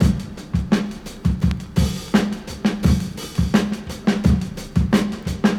• 86 Bpm HQ Drum Groove F Key.wav
Free drum loop - kick tuned to the F note. Loudest frequency: 572Hz
86-bpm-hq-drum-groove-f-key-y1y.wav